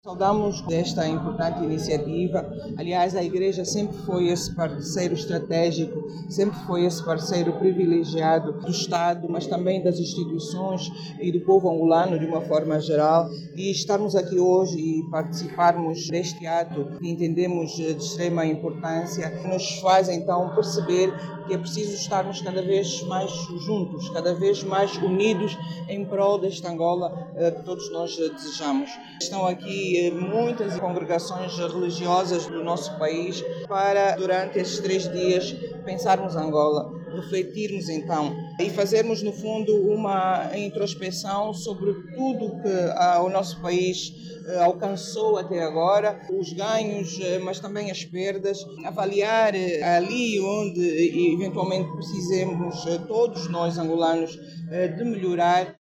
A Comissão Inter-Eclesial realizou, nesta terça-feira, em Luanda, uma Conferência Ecuménica sobre a contribuição da Igreja e o seu papel para o desenvolvimento social.
Na ocasião, a vice-presidente do MPLA, Mara Quiosa, felicitou a Igreja pelo trabalho que tem vindo a desenvolver em prol da paz em Angola.